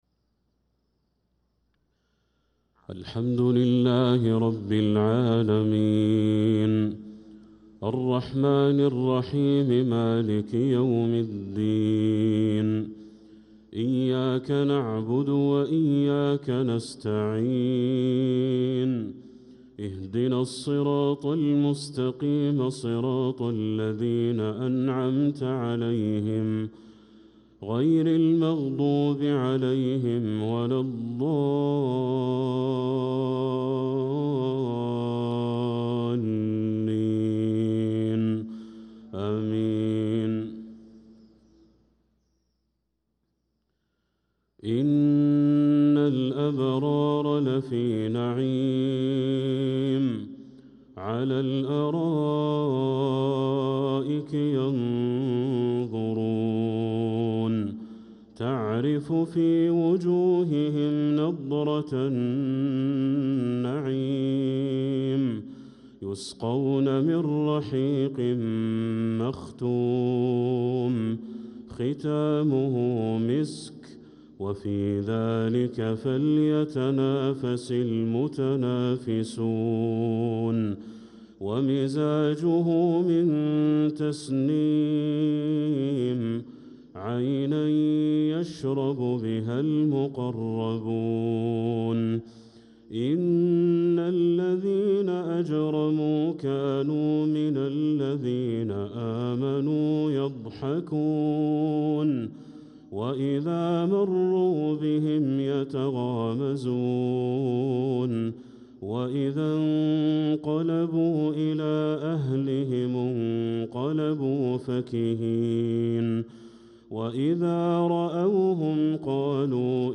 صلاة المغرب للقارئ بدر التركي 17 جمادي الأول 1446 هـ